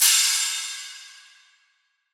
DDW4 CRASH 2.wav